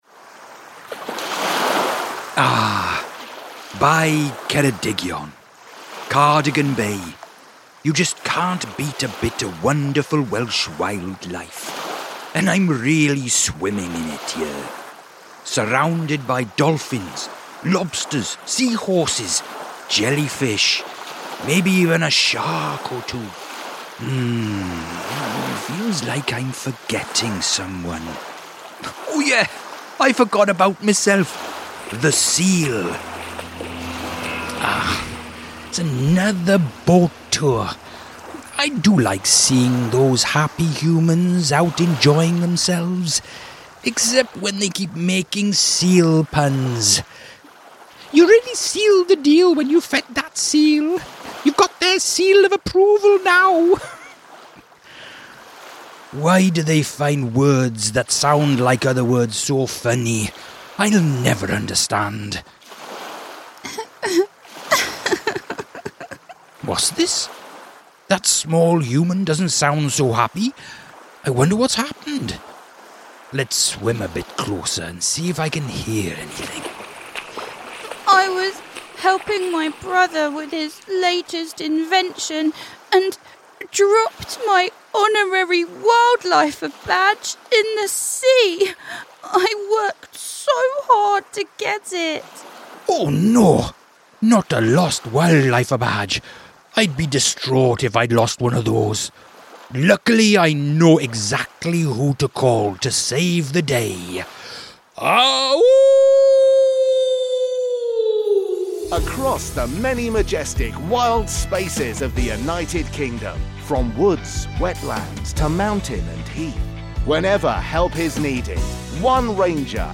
All other characters played by members of the ensemble.